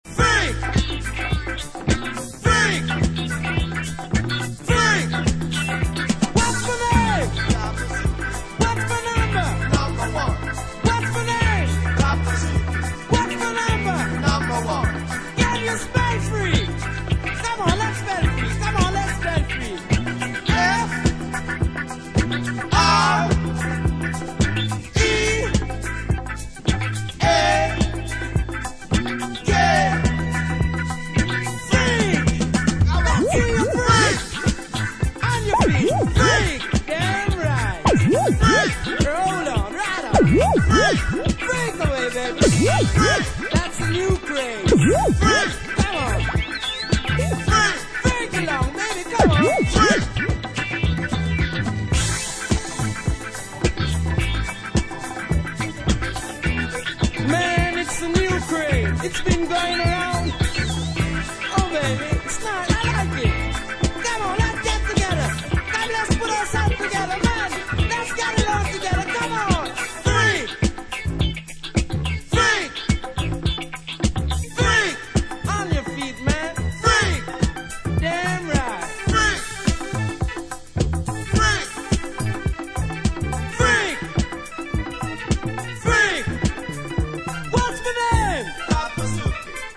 reggae dub-disco